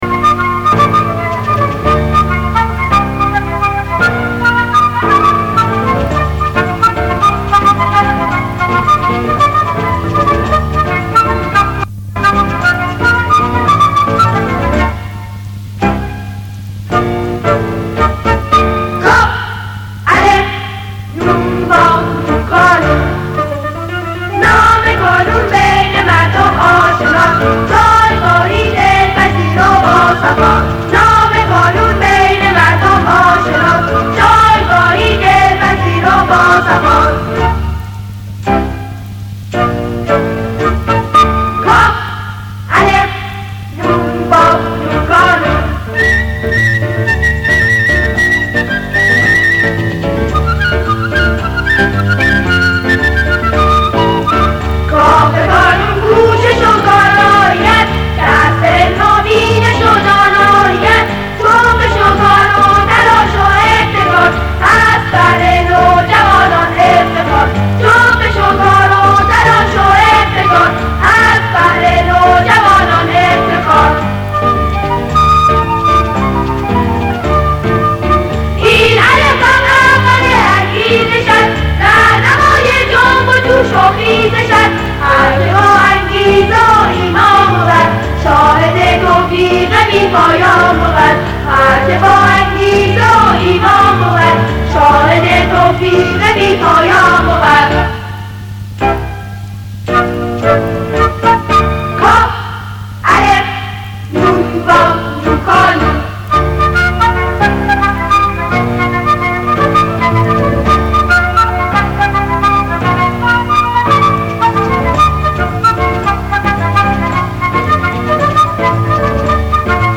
سرود کانون